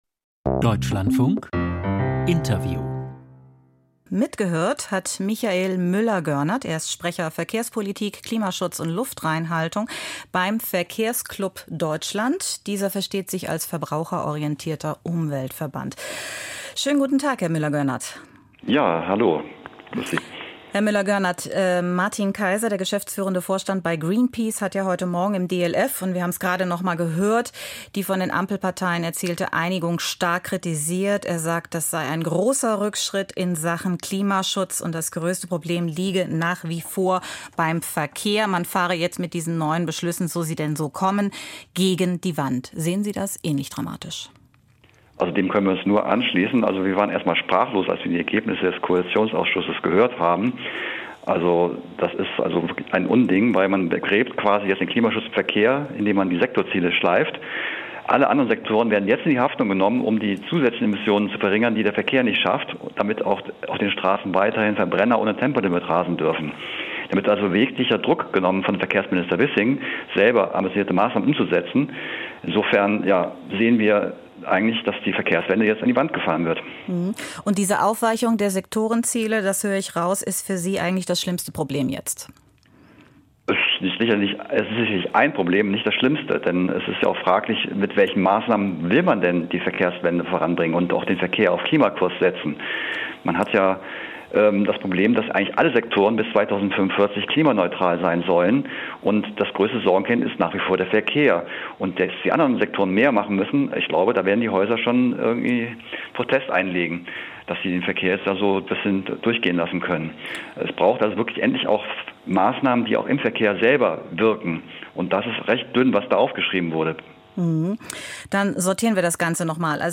Verkehrswende - Interview